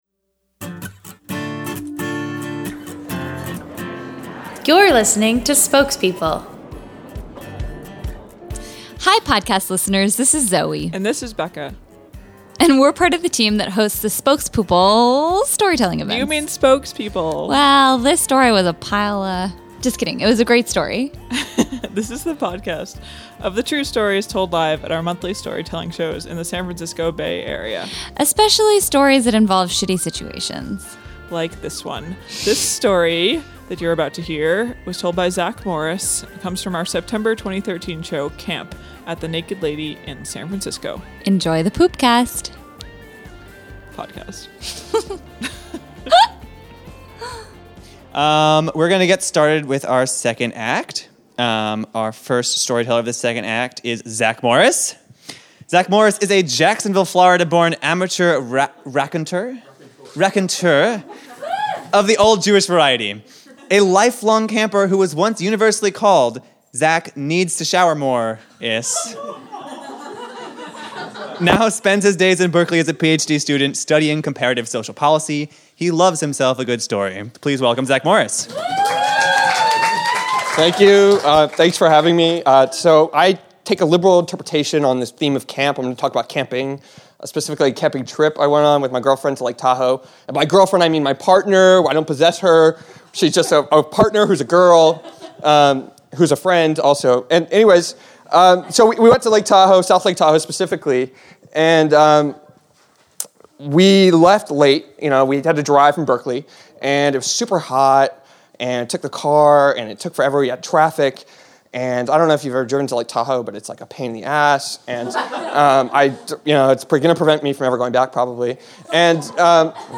Homepage / Podcast / Storytelling
Nothing brings a couple together like a challenge to face together — or so we’re told. This story was performed live at our September 2013 show, “Camp.”